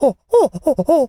Animal_Impersonations
monkey_chatter_12.wav